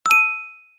Barulhinho De Notificação
Barulhinho de notificação no celular (notification).
notificacao.mp3